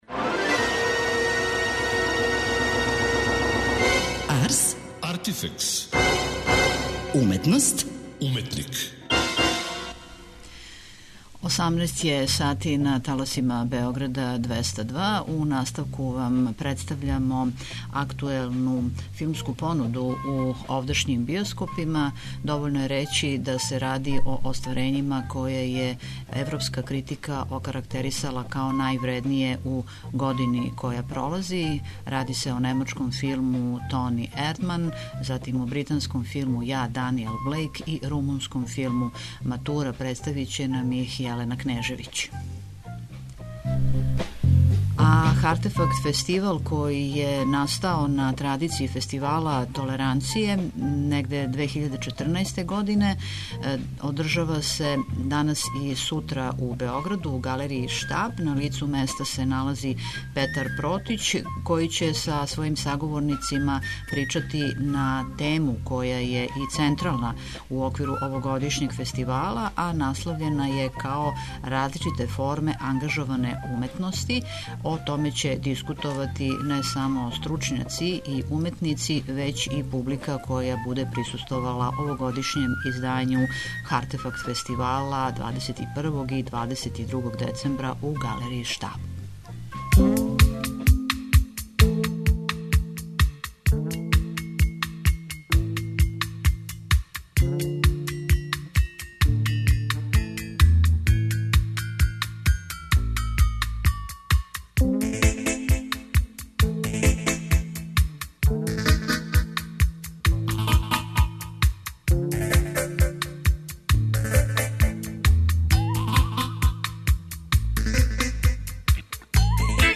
Уживо се са Хартефакт Фестивала